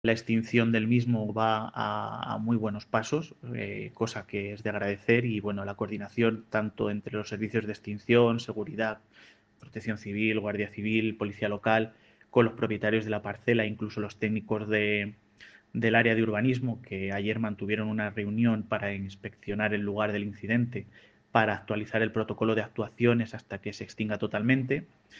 Declaraciones del alcalde Miguel Aparicio 1